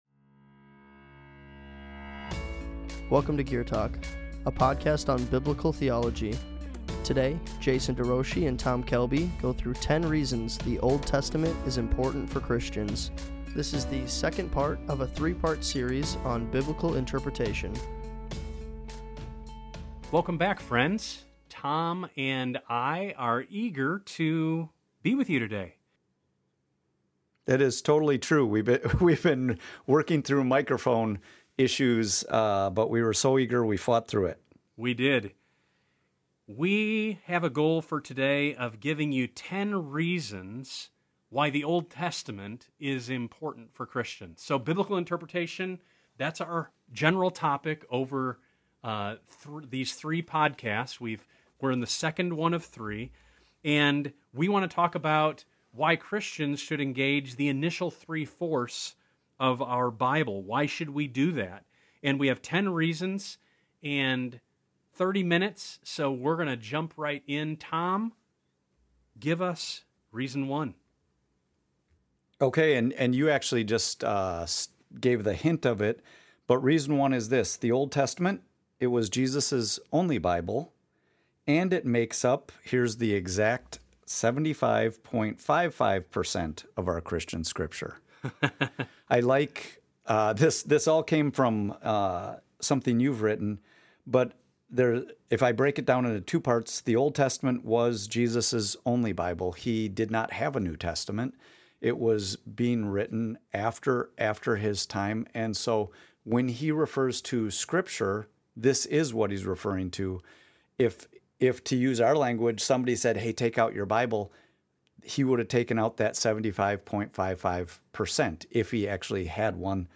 We’ve been working through microphone issues, but we were so eager, we fought through it.